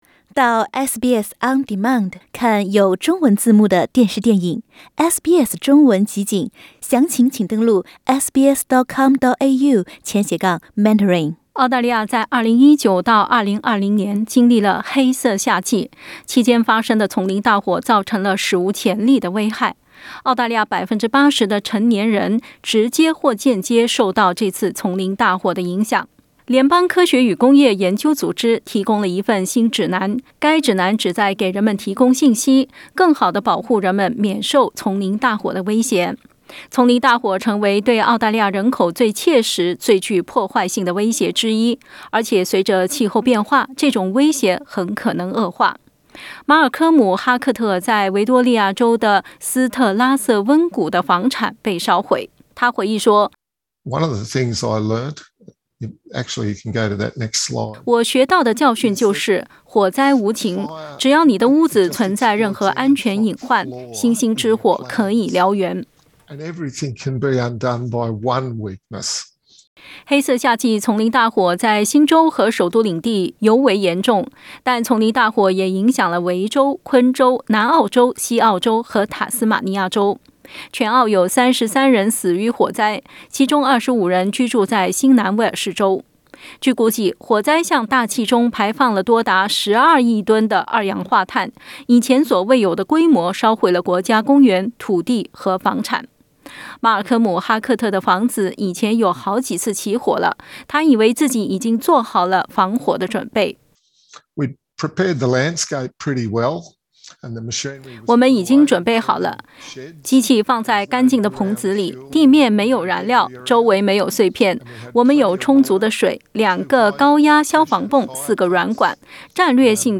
(點擊圖片收聽報道)